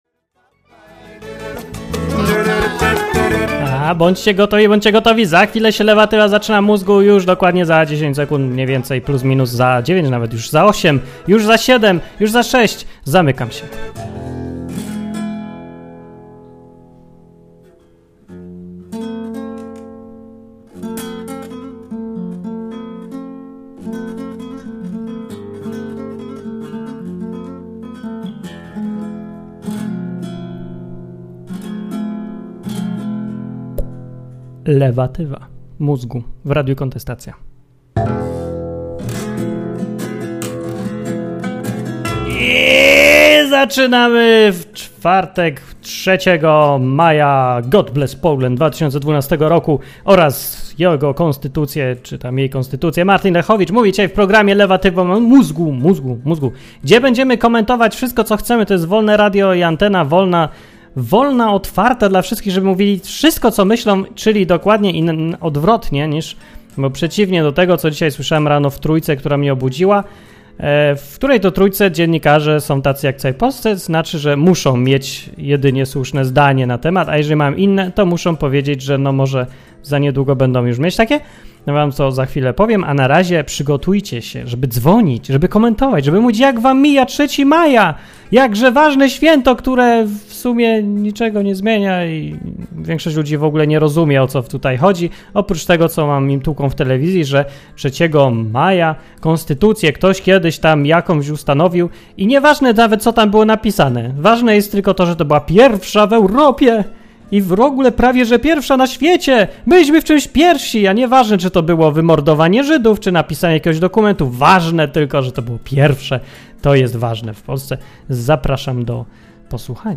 Były informacje, komentarze, słuchacze, wszystko co najlepsze.